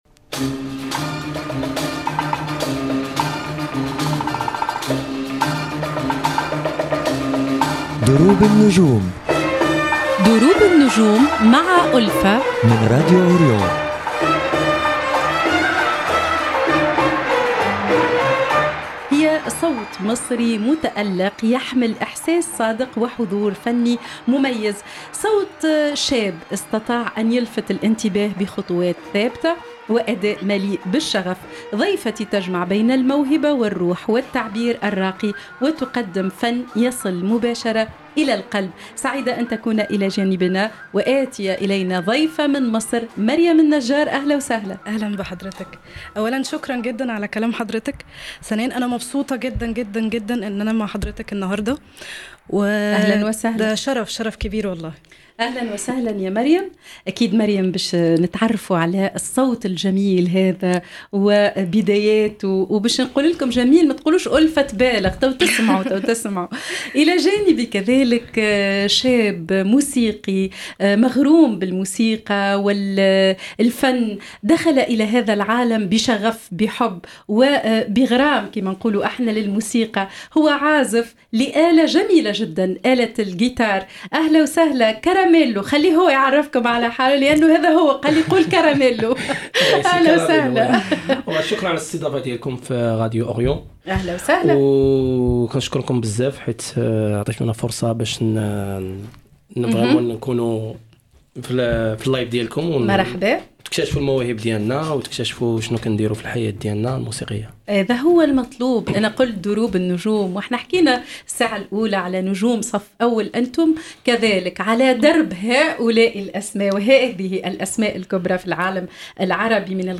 تمتلك ضيفتنا خامة صوتية مميزة تذكر بجيل عمالقة الغناء العربي حيث تجمع بين القوة والإحساس والتمكن في الأداء.
في هذه الحلقة نقترب من تجربتها وبدايتها كما نتعرف إلى مشاريعها الفنية القادمة ورؤيتها لمستقبل الأغنية الأصيلة بصوت الجيل الجديد. حلقة مليئة بالشغف والنغم الجميل نكتشف فيها صوتا صاعدا يحمل ملامح الزمن الذهبي بروح اليوم.